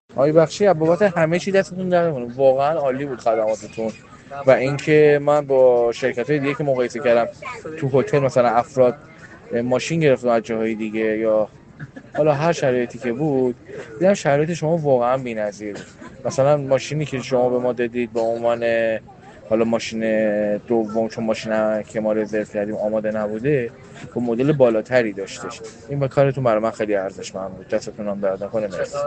تجربه اجاره خودرو در دبی را از زبان مشتریان کاراپلاس بشنوید